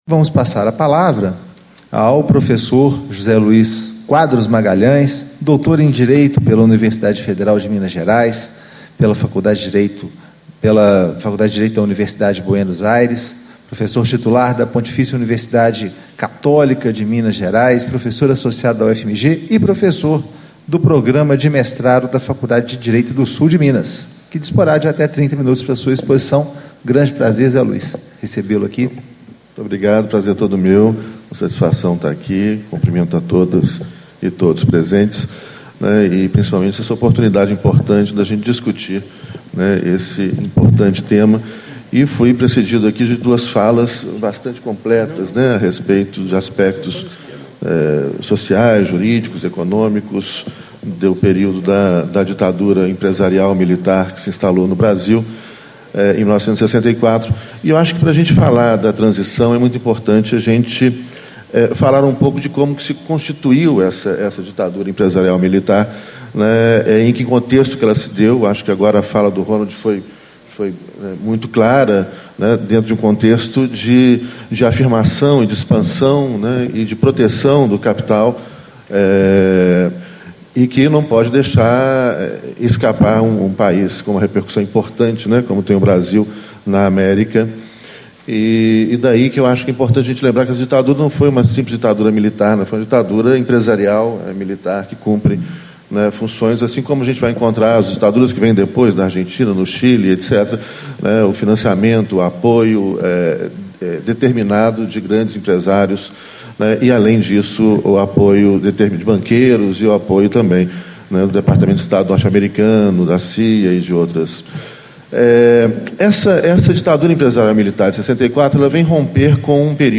Discursos e Palestras